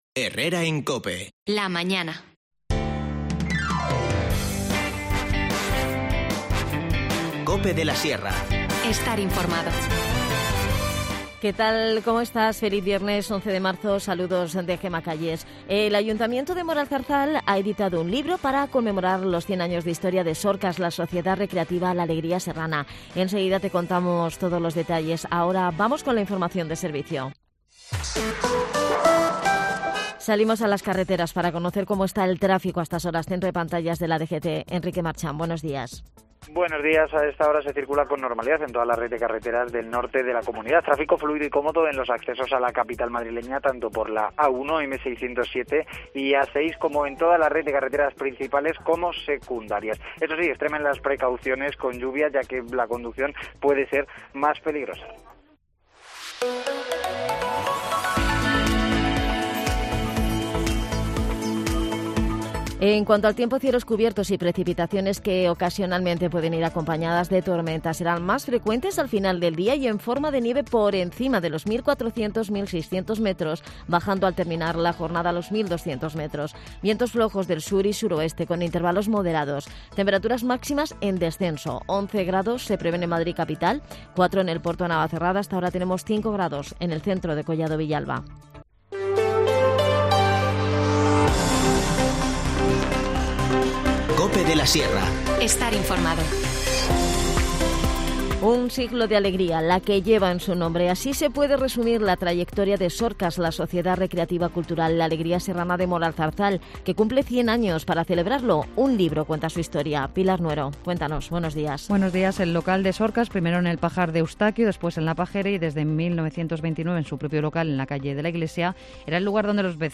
Nos cuenta todos los detalles Miguel Particda, concejal de Juventud y Deportes.
Las desconexiones locales son espacios de 10 minutos de duración que se emiten en COPE, de lunes a viernes.